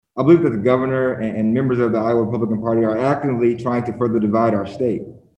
Representative Ras (like “Ross”) Smith, a Democrat from Waterloo, says the Republican majority in the legislature is leaving a key part out.
Four other black members of the Iowa House joined Smith for an online news conference this (Thursday) morning.